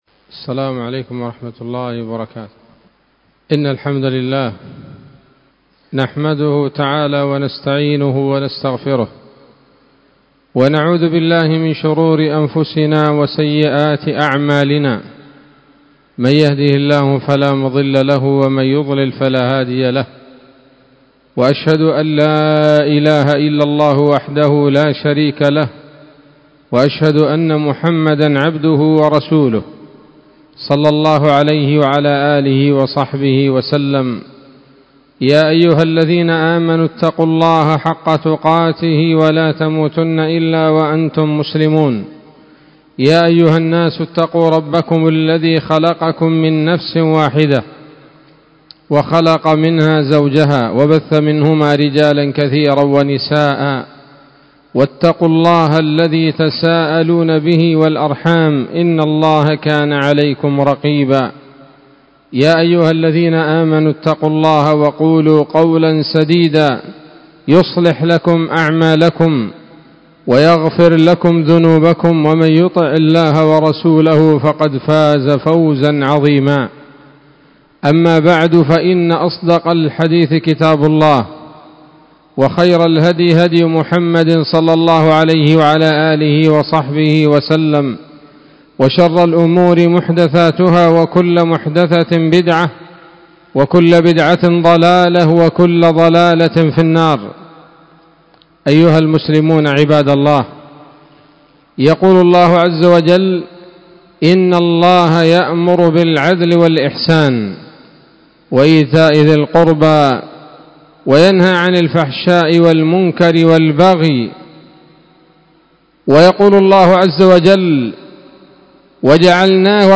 خطبة جمعة بعنوان: (( تحذير المؤمنين من الغلو في الدين )) 19 ذو الحجة 1444 هـ، دار الحديث السلفية بصلاح الدين